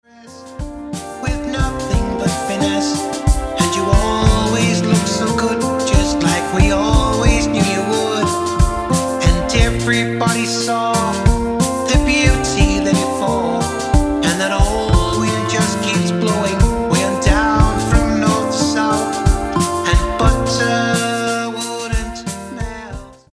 Lovely Ballad